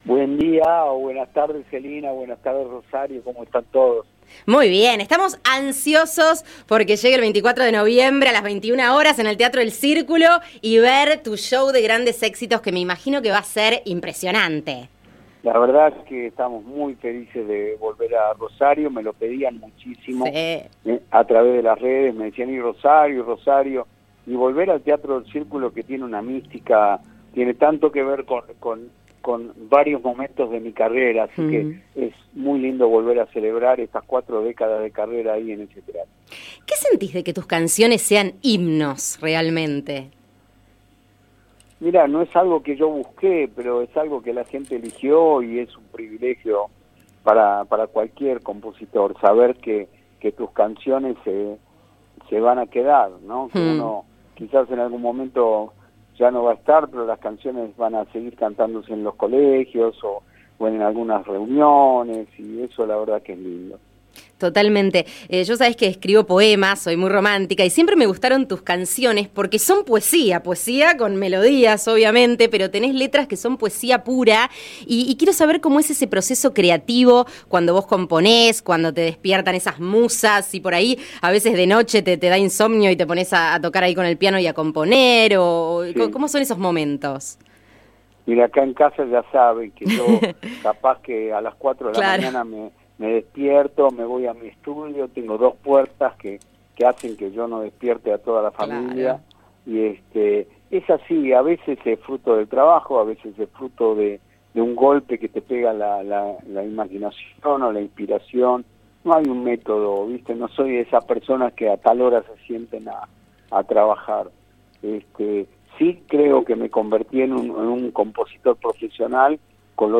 Volvé a escuchar la entrevista y deleitate con las anécdotas e historias sobre su carrera que Lerner compartió en «De Pantallas y Telones».